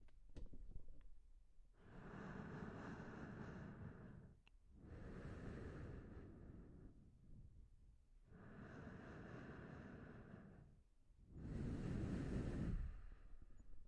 female breathing
描述：female heavy breathing in a very small space, no reverbrecorded with tascam HDP2 and MKH416
标签： breathing female heavy
声道立体声